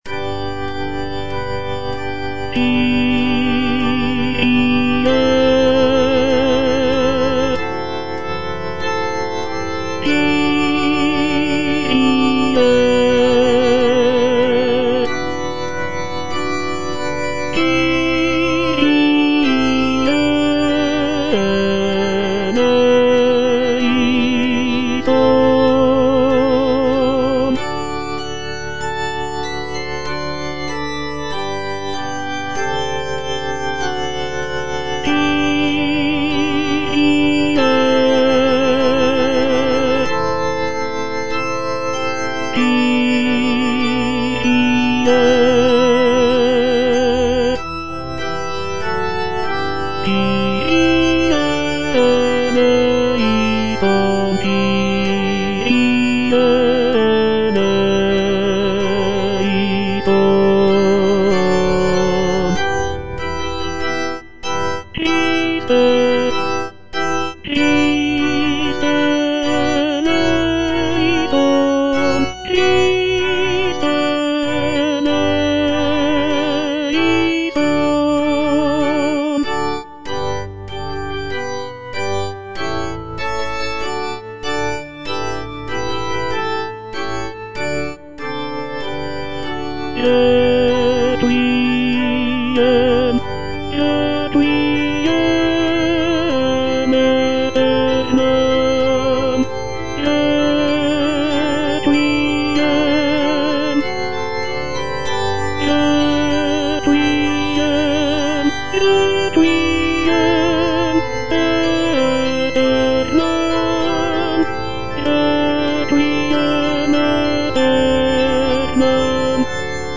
Tenor (Voice with metronome) Ads stop
is a sacred choral work rooted in his Christian faith.